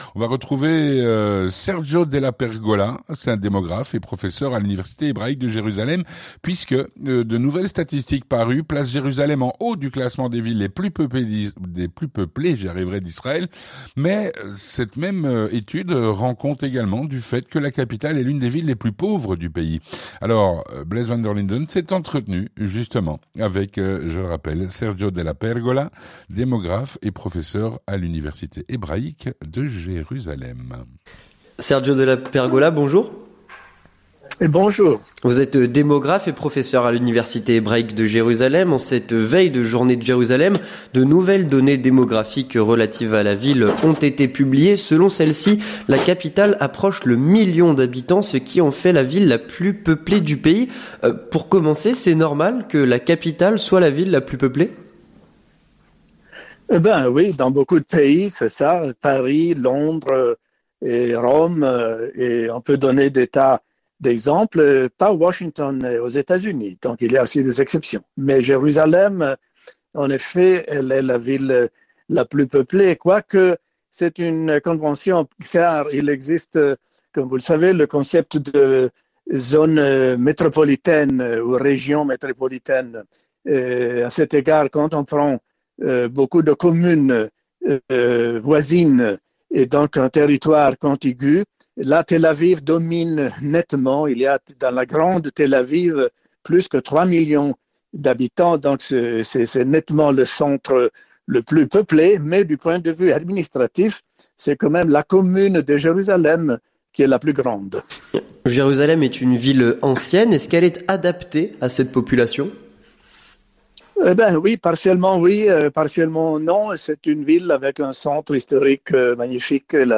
Entretien du 18h - Jérusalem ville la plus peuplée et une des plus pauvre d'Israël